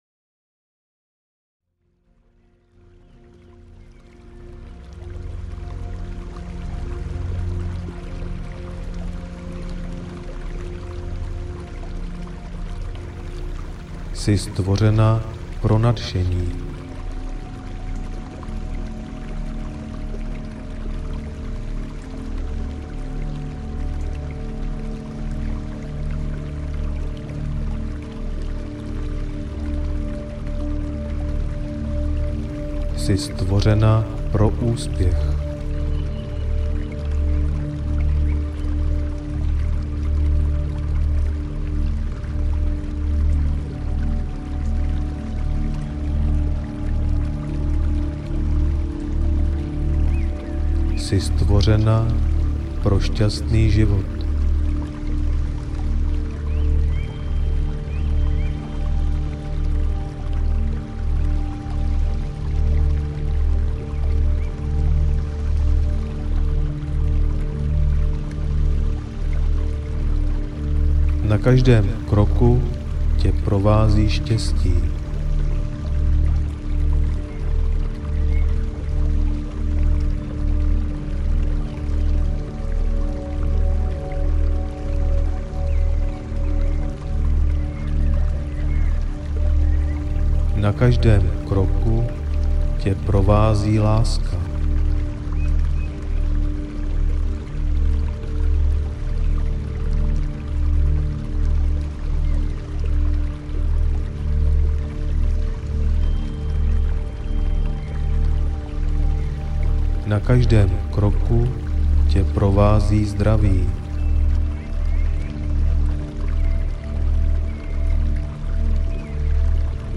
Motivační spánek pro ženy audiokniha
Ukázka z knihy